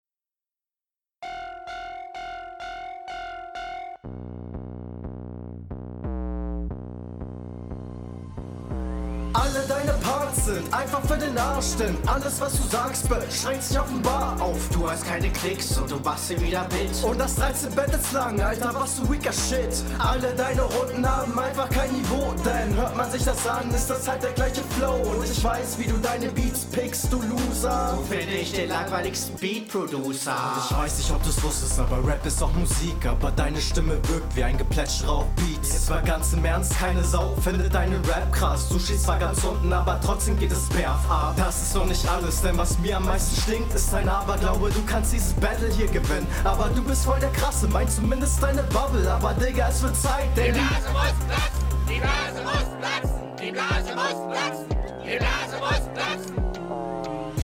das spongebob outro trifft den takt nicht. ich finde ''geplätschere'' ist nicht unbedingt das sinnigste …